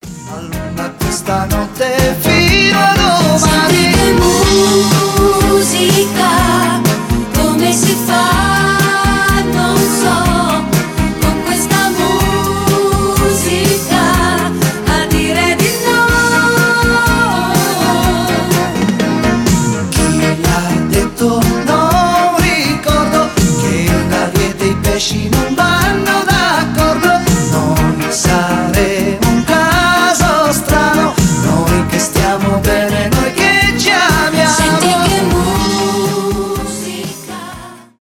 итало диско , танцевальные